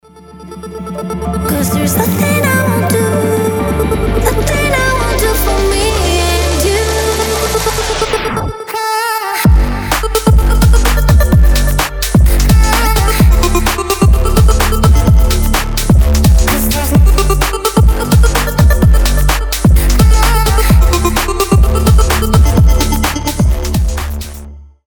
Electropop